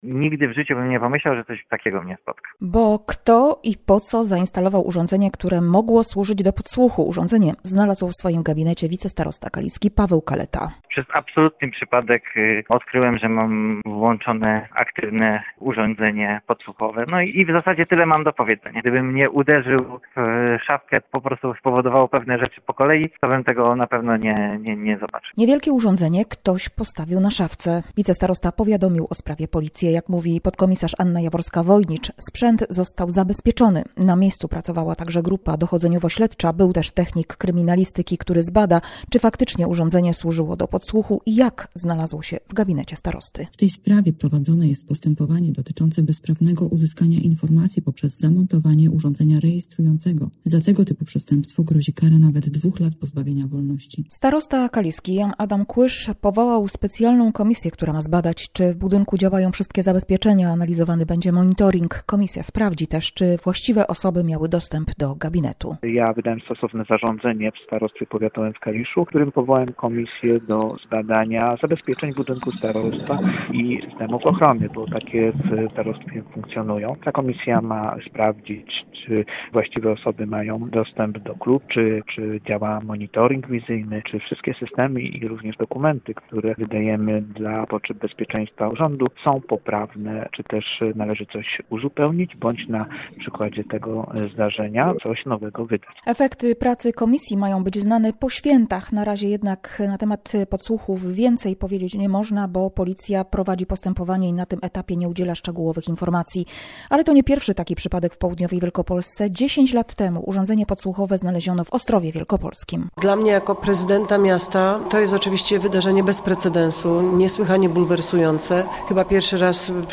- mówi starosta kaliski Jan Adam Kłysz.